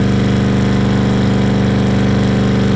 CarEngine.wav